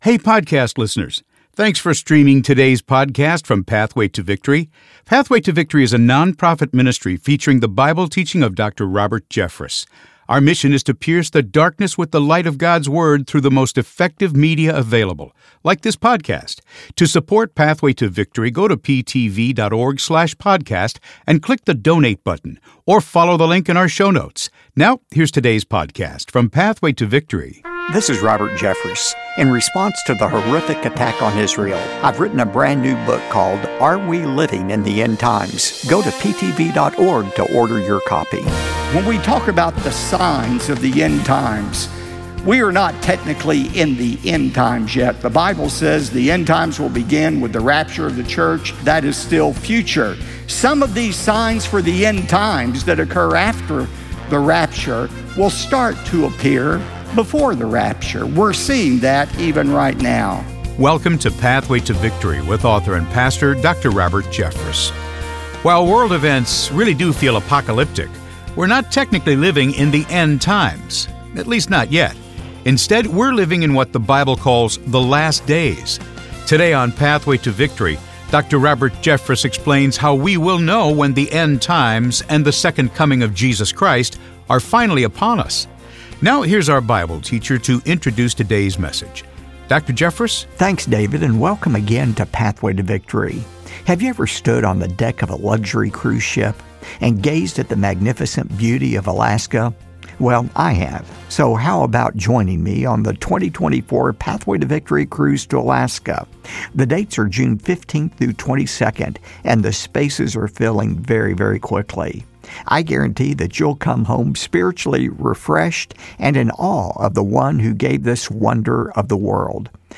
end times Bible prophecy Dr. Robert Jeffress rapture second coming Jesus Christ Bible teaching